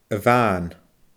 a-bhàn /əˈvaːn/